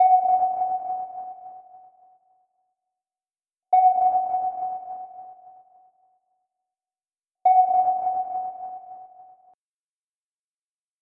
Звуки радара
Шум фиксации цели на радаре